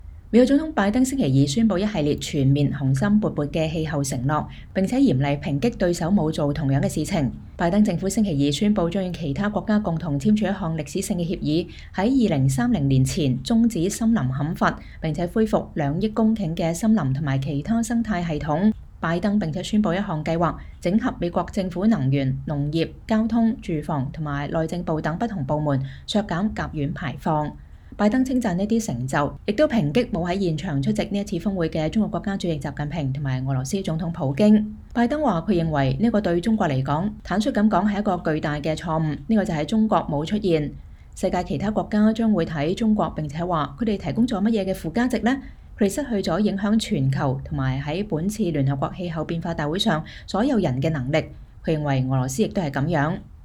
美國總統拜登在格拉斯哥聯合國氣候變化大會上講話（2021年11月2日）